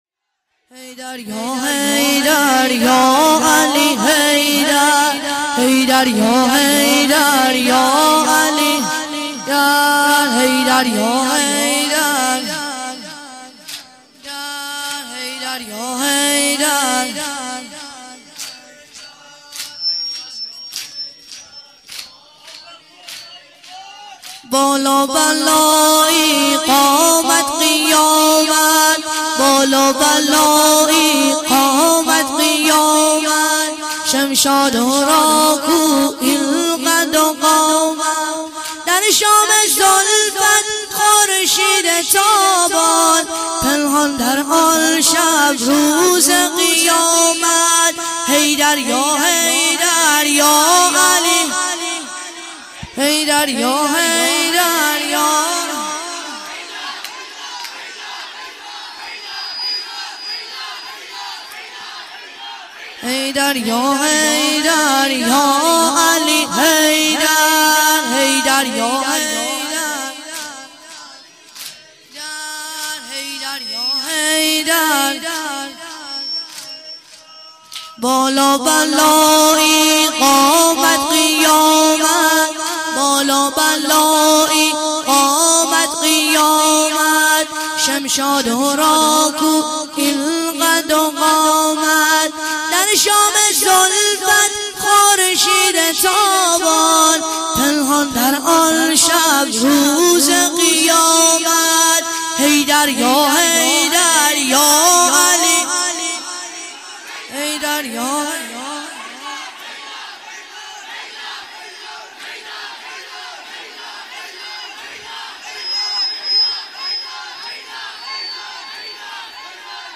بالا بلایی قامت قیامت - مداح